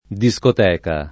discoteca [ di S kot $ ka ] s. f.